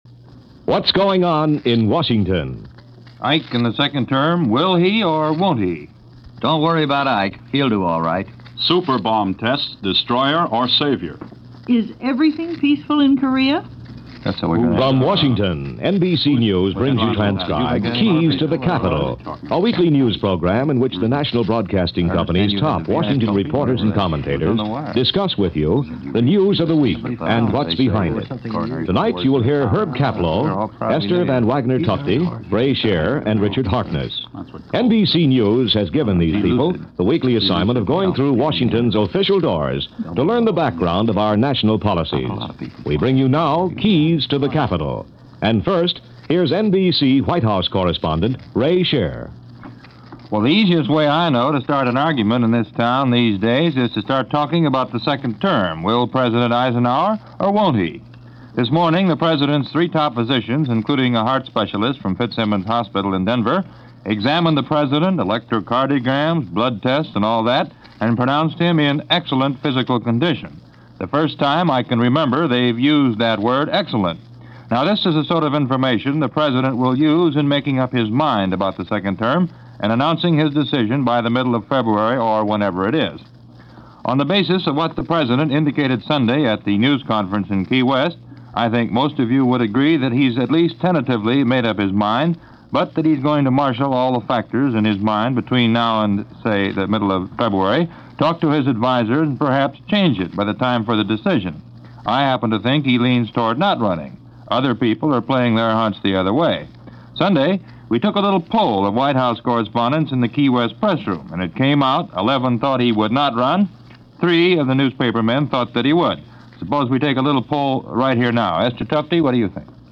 The debate continued – and this was what was on peoples minds, this January 11, 1956, as discussed by a group of reporters as part of the Listen To Washington weekly wrap up series from NBC News.